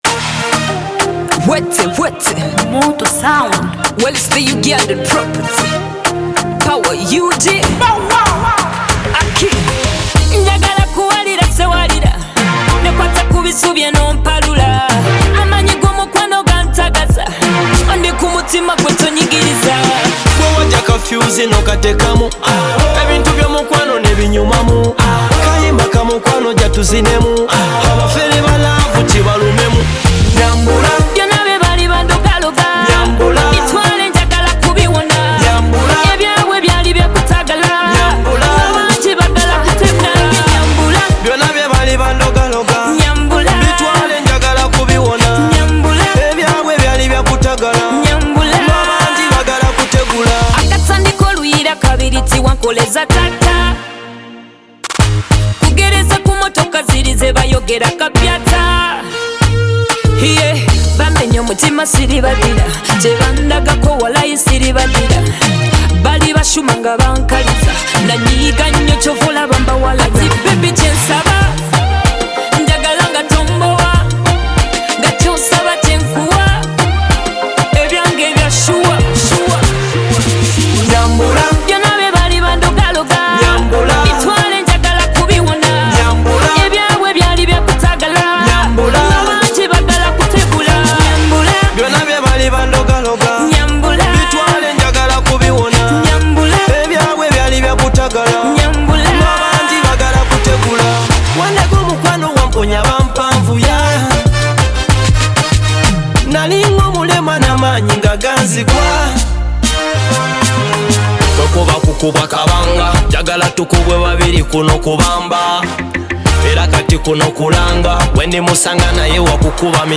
lively energy and memorable hooks